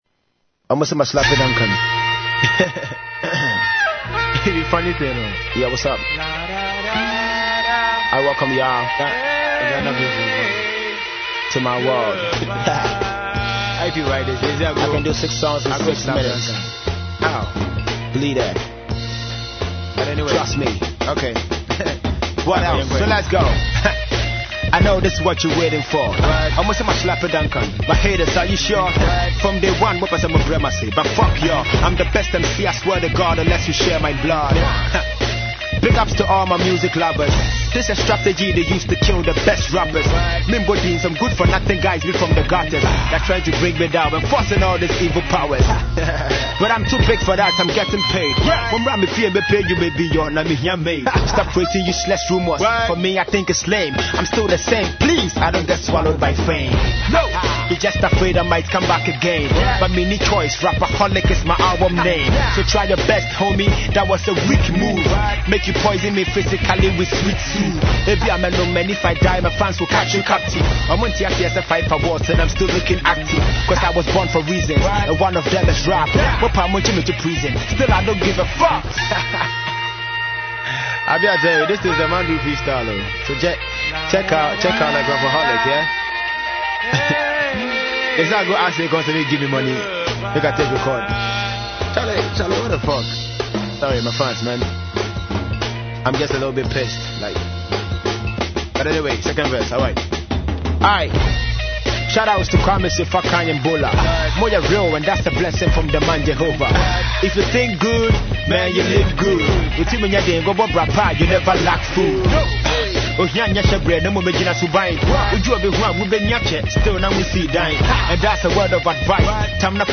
went into the studio to record a whole song for us